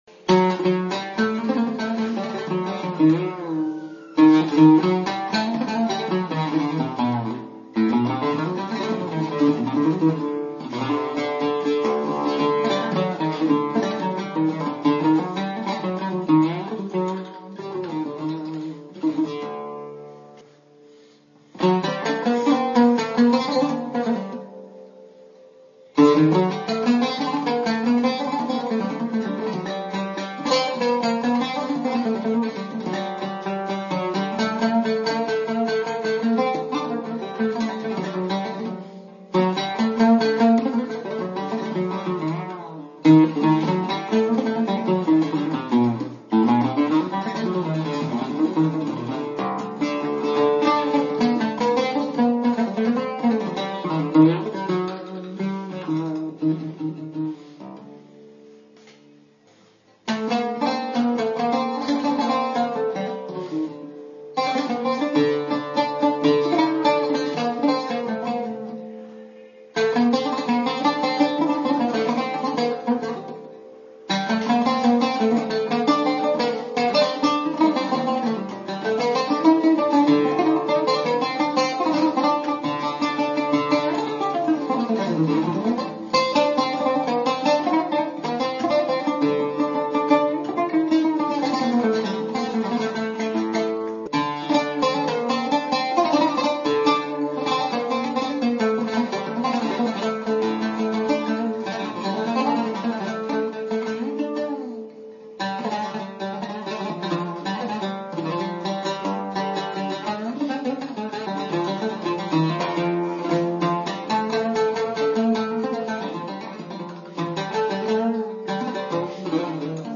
Their sound is gorgeous, deep, "Eastern".
Click here for the sound of the 1916 Roufan oud